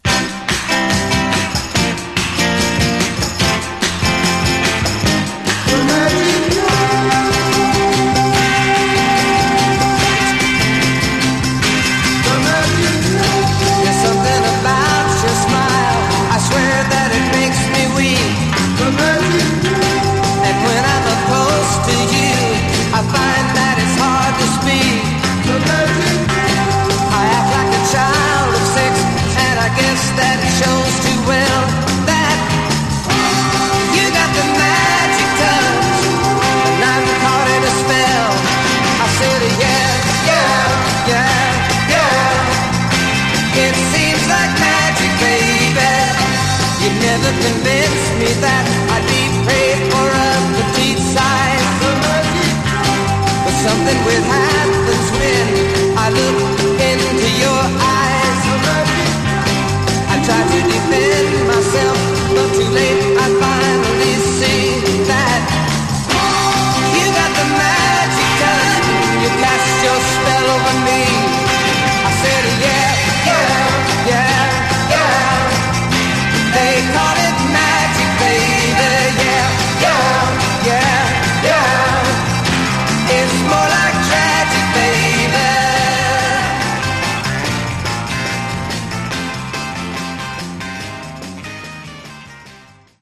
Genre: Garage/Psych